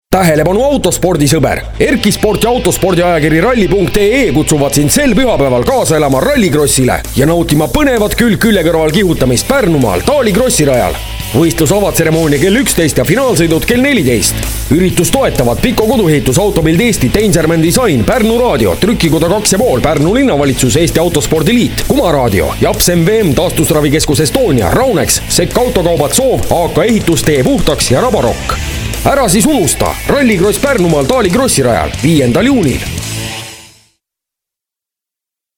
Audioreklaam